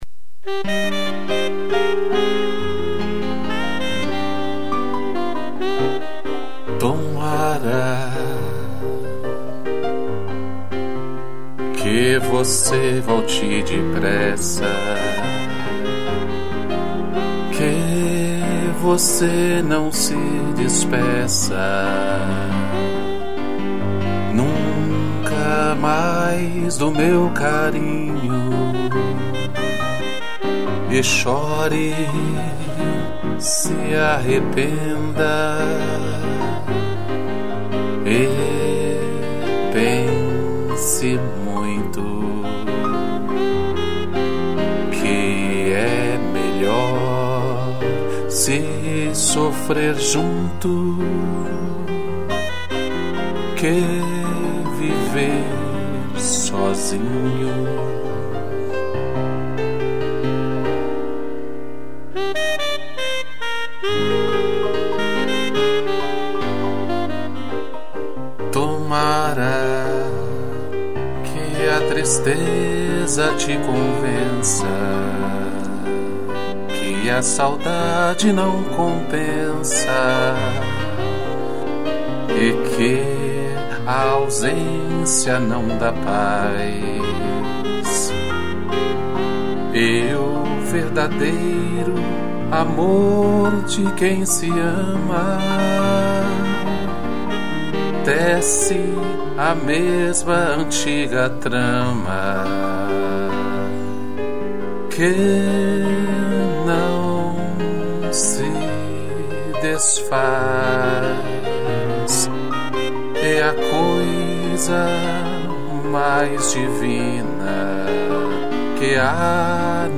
2 pianos e sax
(instrumental)